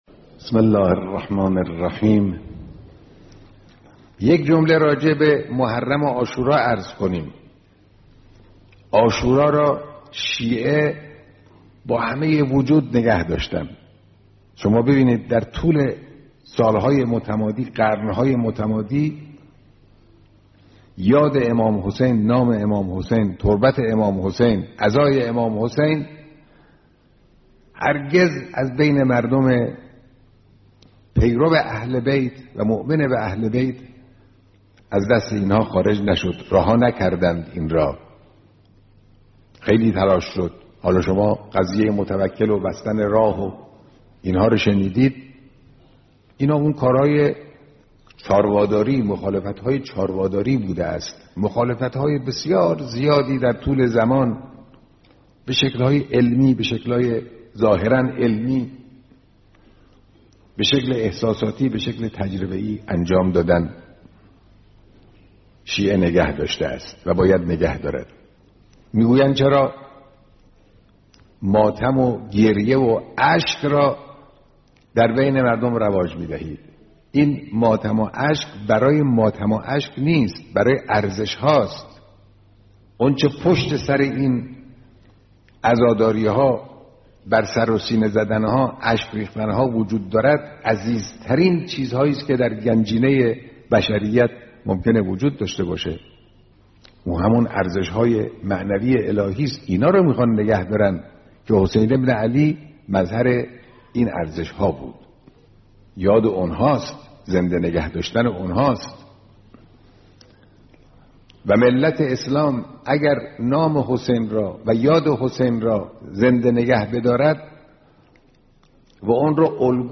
مقتطفات من كلمة الإمام الخامنئي في لقاء مع جمع من الرعيل الأول لقادة ومجاهدي «الدفاع المقدس»
ہفتۂ مقدس دفاع کے موقع پر مقدس دفاع کے کمانڈروں اور سینیئر سپاہیوں سے ملاقات میں تقریر